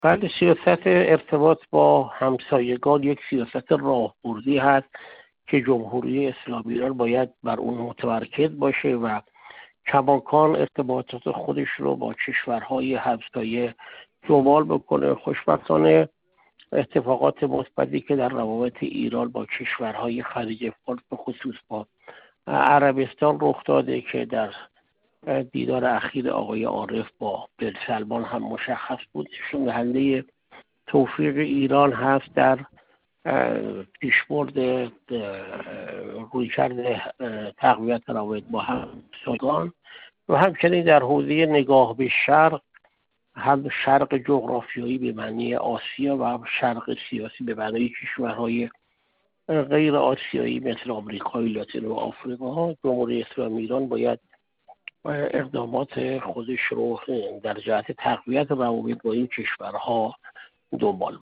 محسن پاک‌آیین، دیپلمات پیشین کشورمان و سفیر اسبق ایران در جمهوری آذربایجان، در گفت‌وگو با ایکنا درباره مشخص شدن گزینه‌های دونالد ترامپ برای وزیر امور خارجه و دفاع و رئیس آژانس مرکزی اطلاعات (سیا) با رویکرد ضدایرانی و جنگ‌طلبانه، گفت: ترامپ اعلام کرد تصمیمات او در این دوره غیرقابل پیش‌بینی خواهد بود و با توجه به این مسئله ما هم نباید درباره احتمالات نوع برخورد ترامپ با ایران، عجله و پیش‌داوری کنیم.